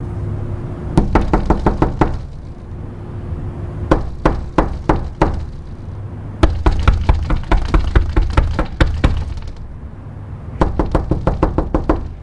mexico city » punching door
描述：Me hitting a door made of glass and wood
标签： glass door wood knock punching
声道立体声